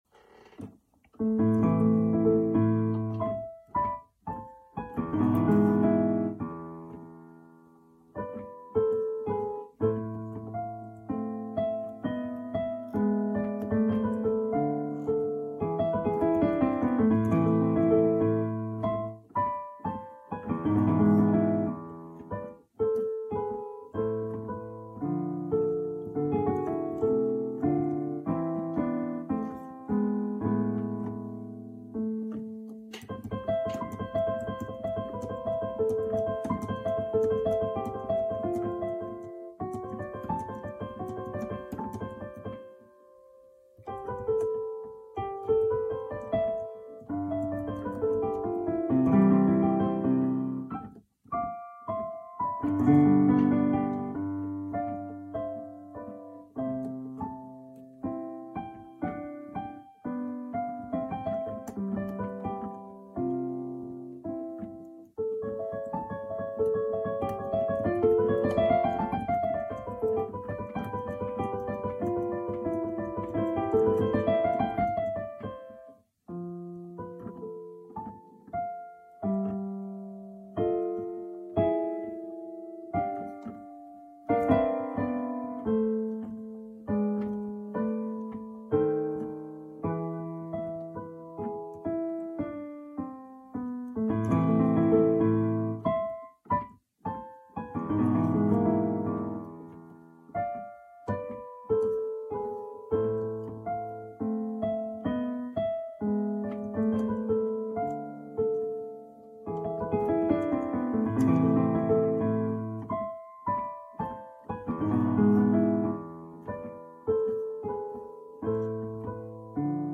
Zongorázás & szerkesztés között /Tra il pianoforte & redazione: Több mint 50 év után találtam rá Pleyel és Benda ezen szonatináira / L'ho ritrovati dopo più di 50 anni >> és tegnap éjjel több mint fél évszázad után igyekeztem újra eljátszani, amiben a felvételkor készített lámpaláz csak akadályozott... / e ieri notte dopo di più di mezzo secolo ho cercato di risuinare, ma nella registrazione la forte ansia da riflettori/registrazione m'ha fortemente fortemente ostacolata...